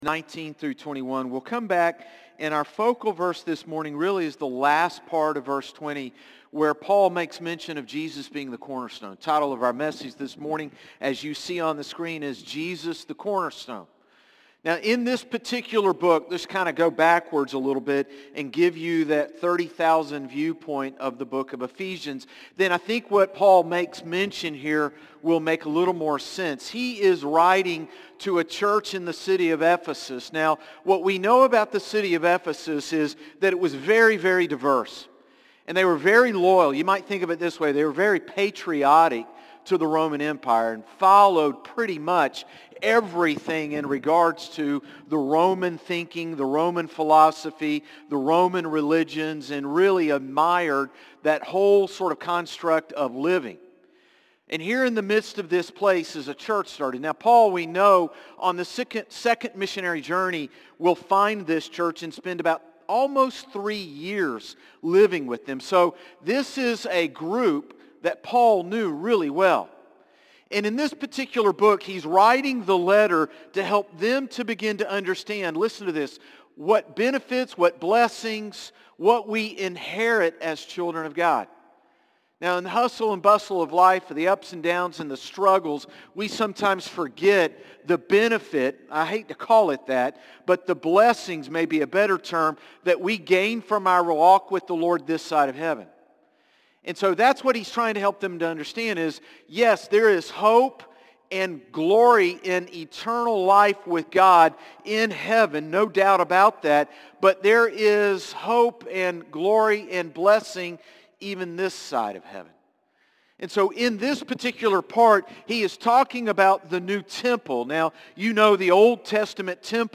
Sermons - Concord Baptist Church
Morning-Service-9-15-24.mp3